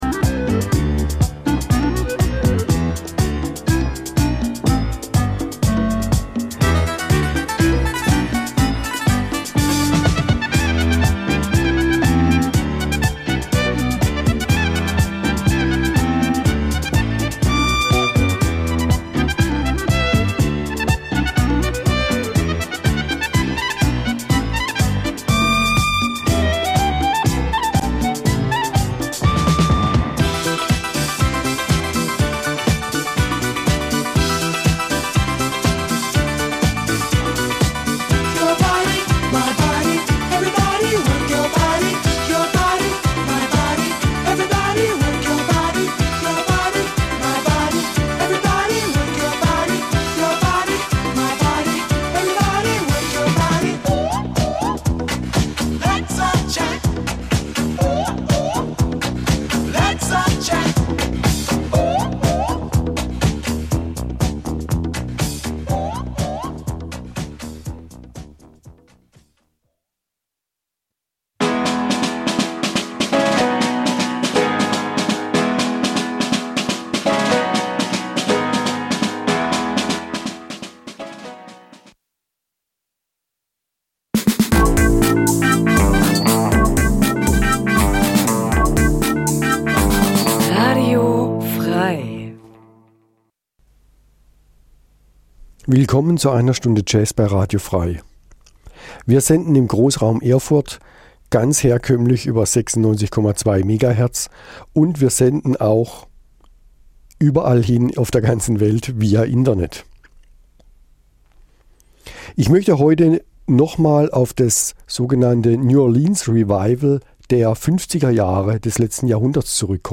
Eine Stunde Jazz